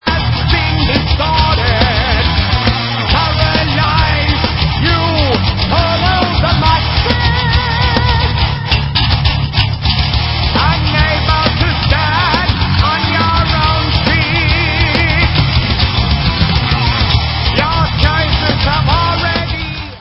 PROG. THRASH METAL